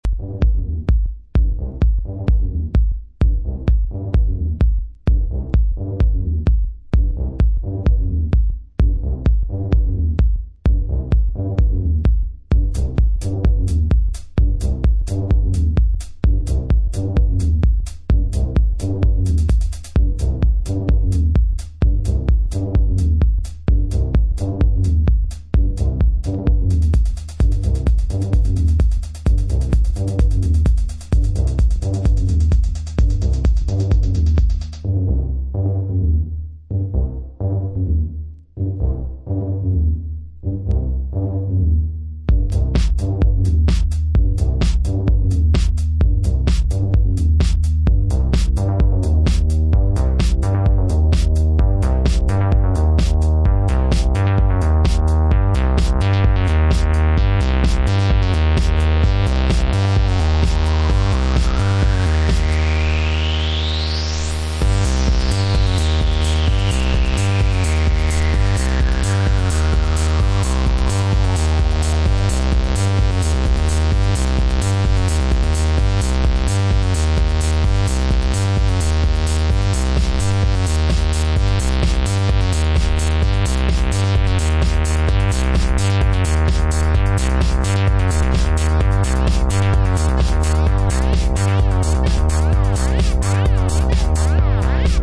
mad raving techno tracks